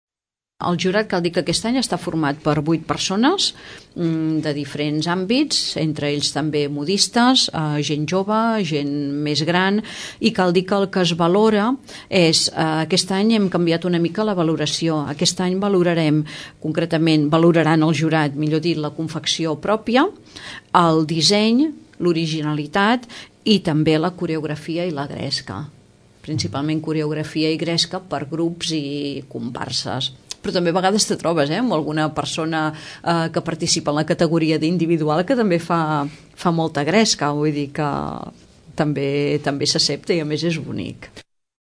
La regidora de festes, Maria Àngels Cayró recorda quins aspectes valorarà el jurat en el concurs de disfresses.